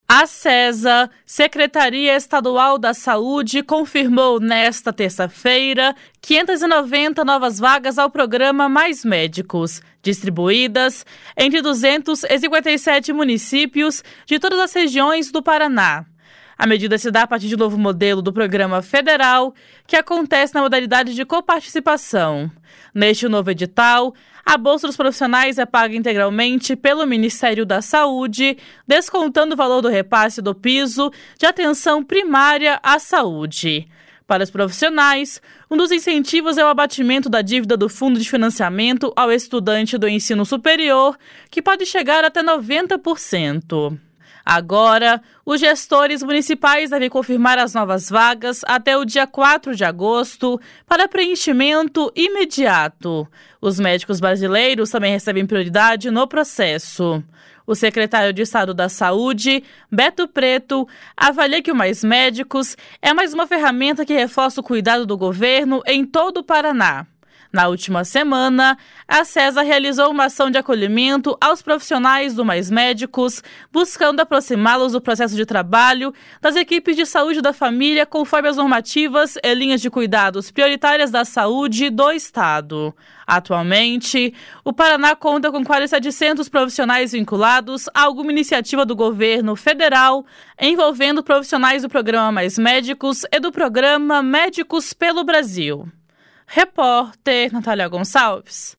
O secretário de Estado da Saúde, Beto Preto, avalia que o Mais Médicos é mais uma ferramenta que reforça o cuidado do governo em todo o Paraná.